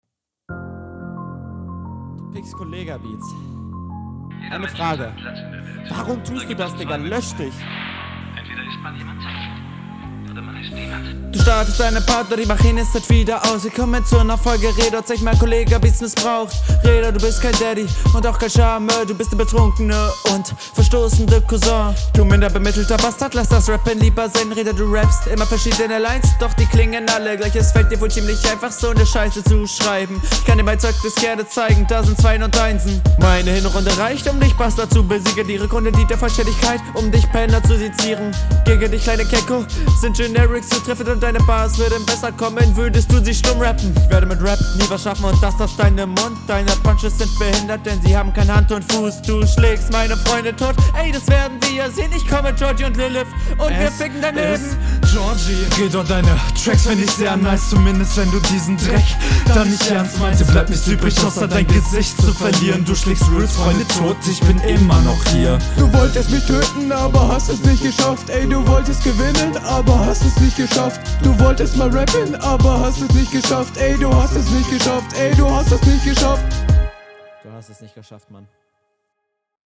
finde den flow leider zu holprig